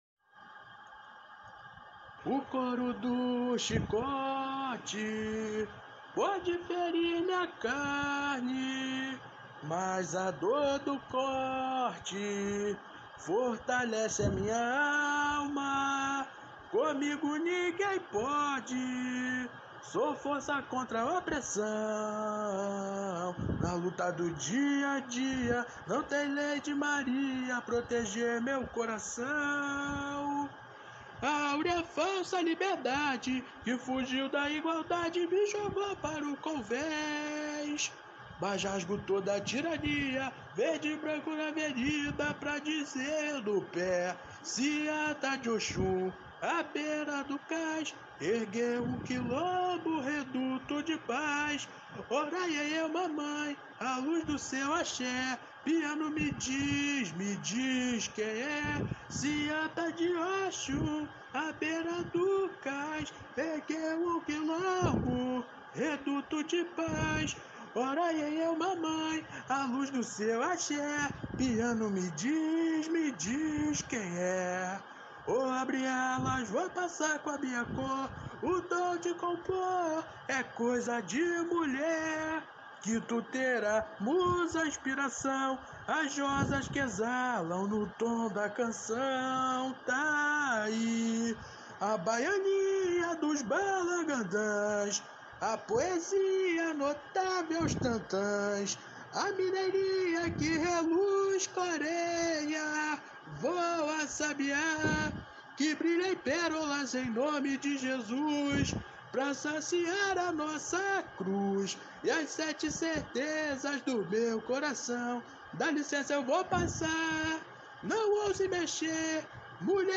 Samba  02